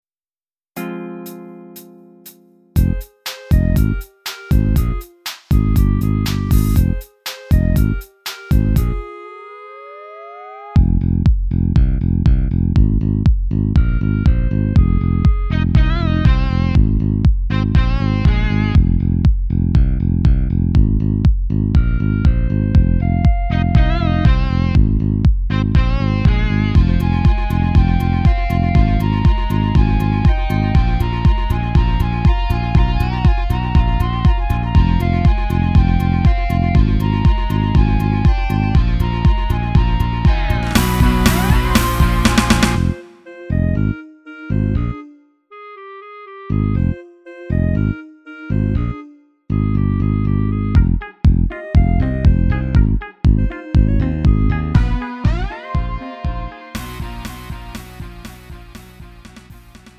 음정 원키 2:54
장르 구분 Lite MR